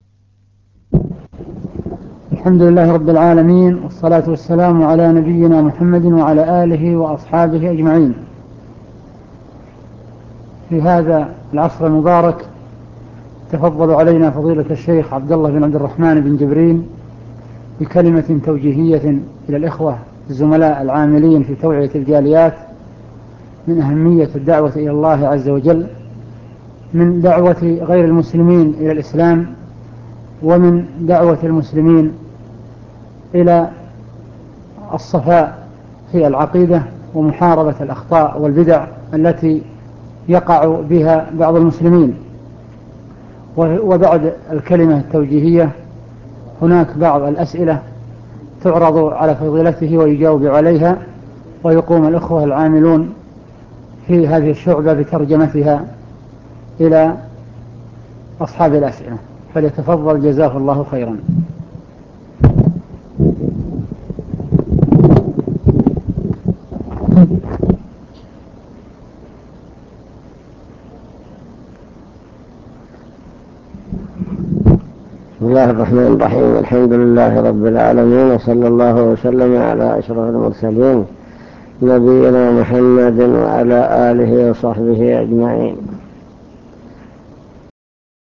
المكتبة الصوتية  تسجيلات - لقاءات  لقاء مع الشيخ بمكتب الجاليات
تقديم